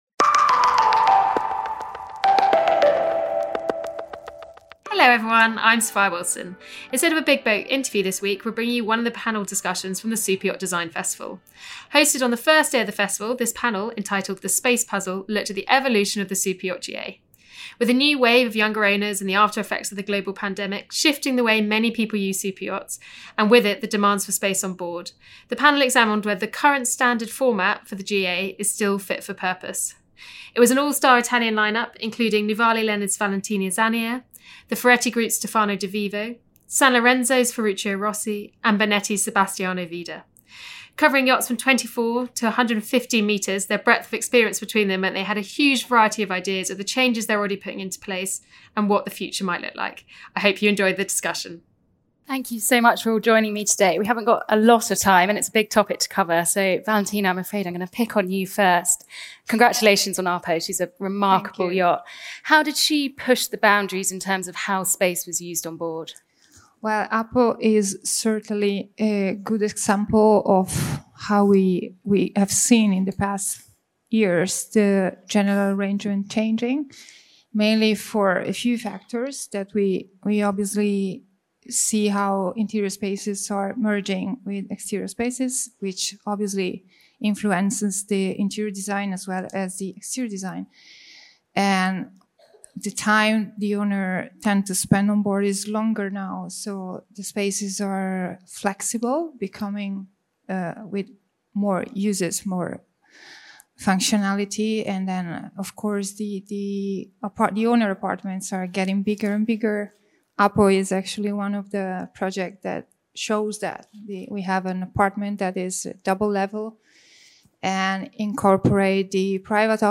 In this week’s Big BOAT Interview we take you back to this year’s Superyacht Design Festival, where an all-star Italian panel debated the best use of space on board superyachts.